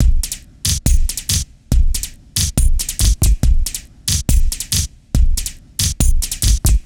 Best Caribbean Loops
Great-Reggae-Beats-70bpm-Song2-Beat2Only.wav